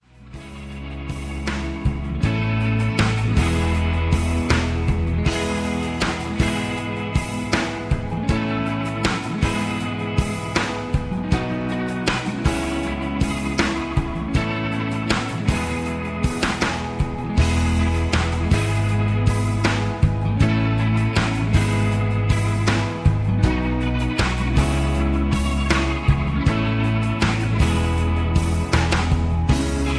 karaoke, mp3 backing tracks
rock